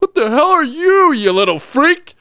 WELDER-SHOO6.mp3